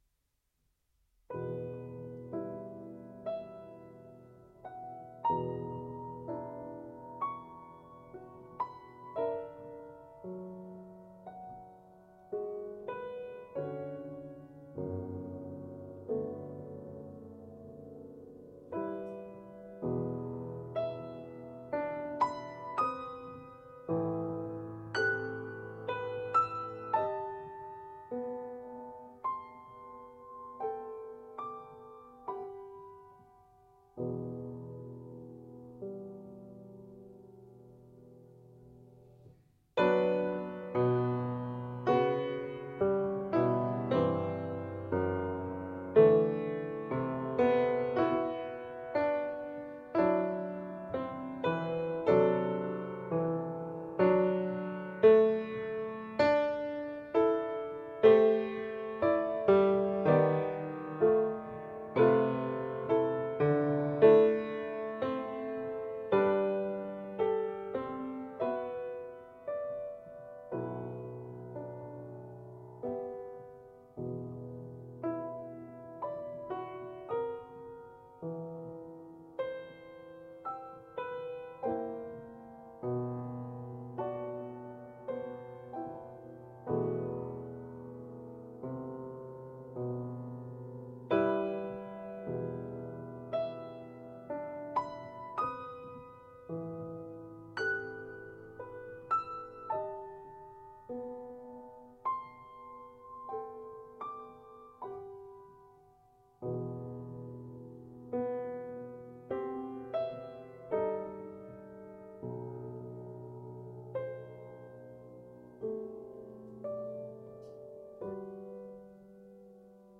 Lento — molto cantabile http